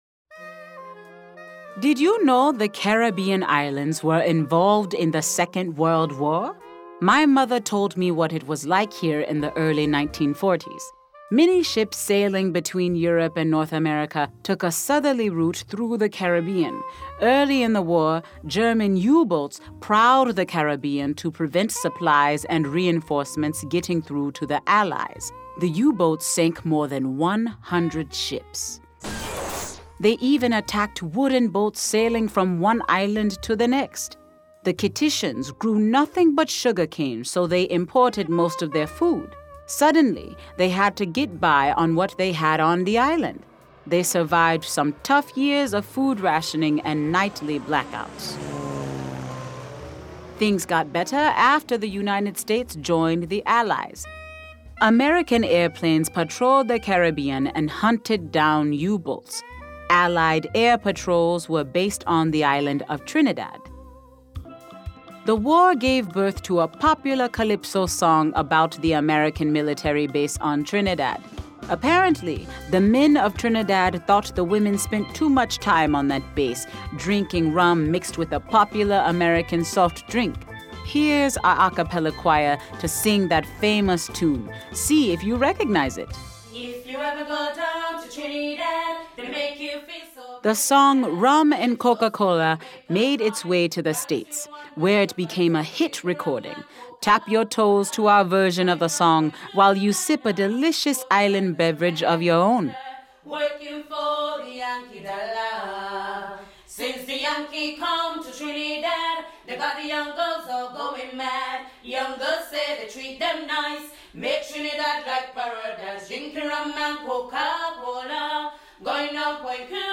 GPS-Triggered Audio Tour Samples